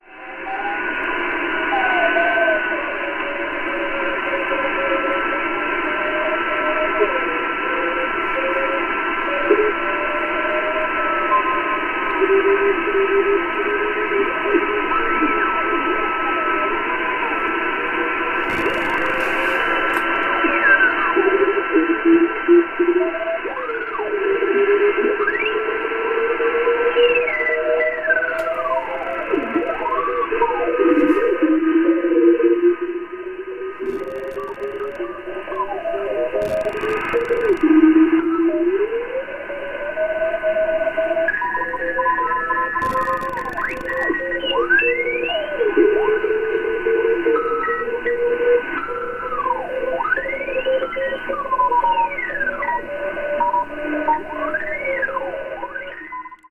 まだあまり知られていない衛星なので、コールする局もまばら。一部録音しましたので、